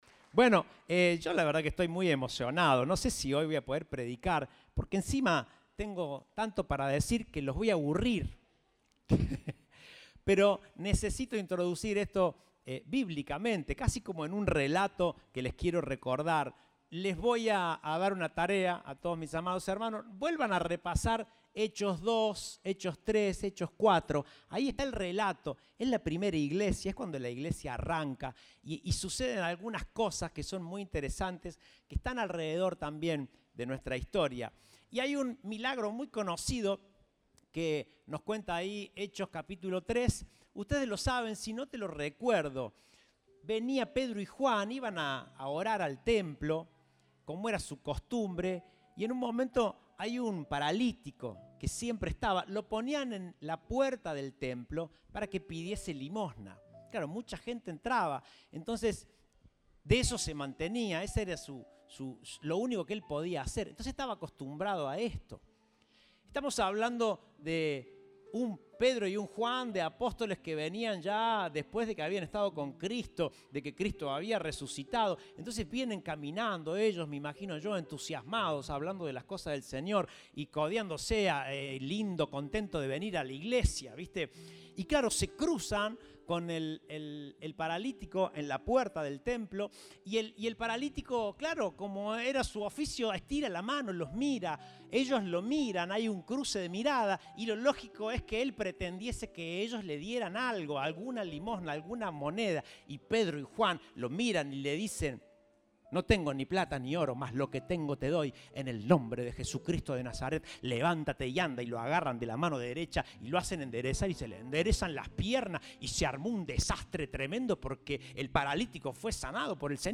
Compartimos el mensaje del Domingo 26 de Marzo de 2023